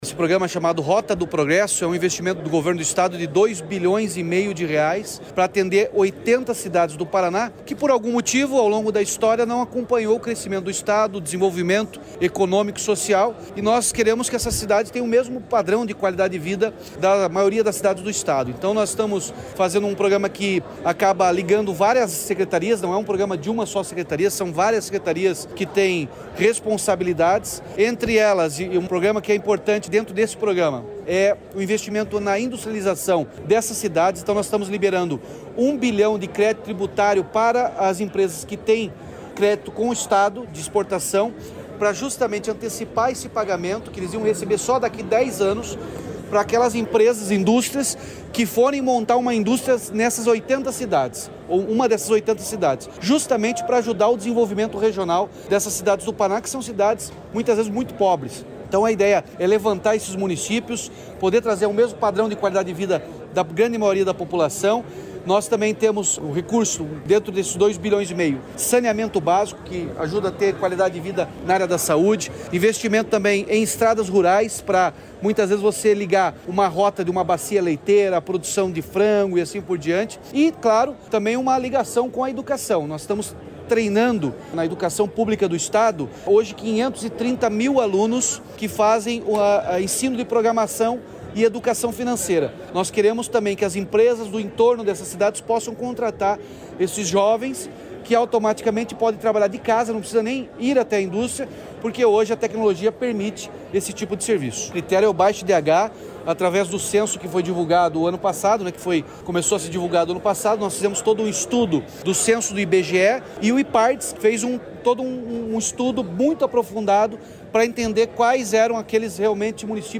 Sonora do governador Ratinho Junior sobre o programa Rota do Progresso